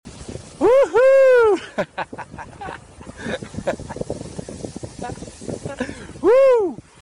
Wohoo.mp3